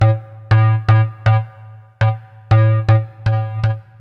loops basses dance 120 - 2
Basse dance 5 G